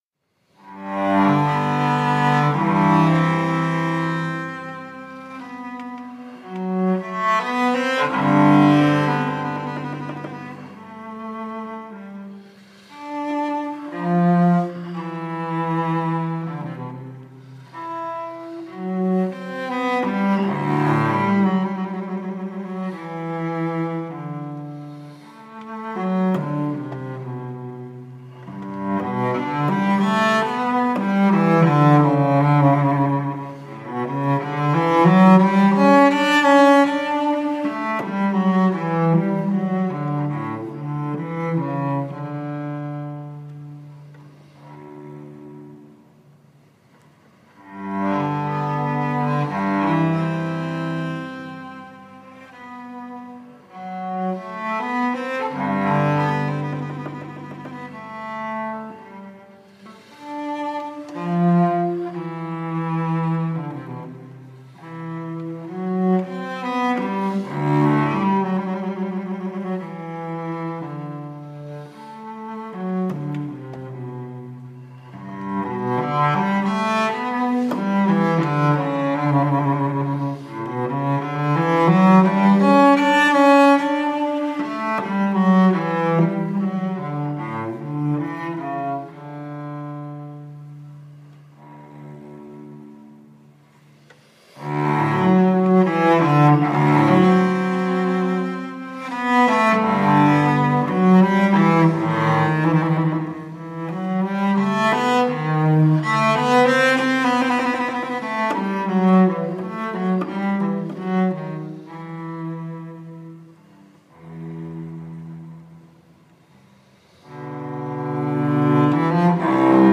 Sanctuary-January-17-audio-v2.mp3